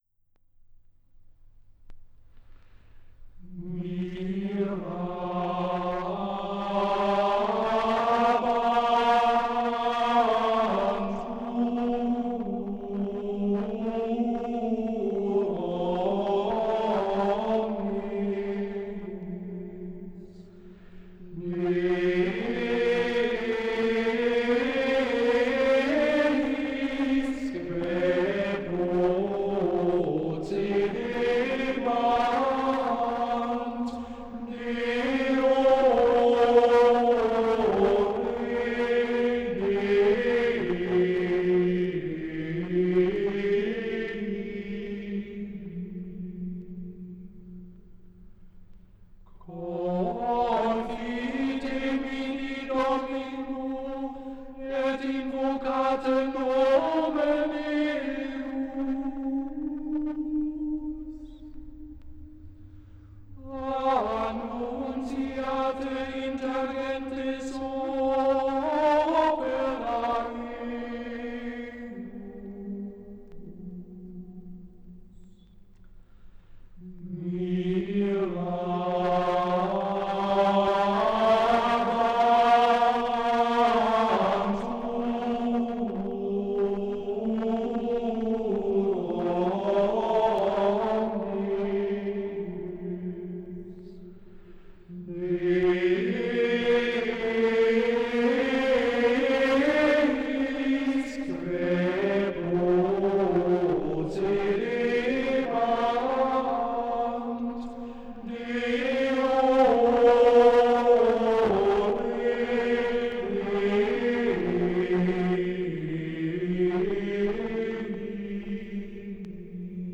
aufgenommen in der Klosterkirche Knechtsteden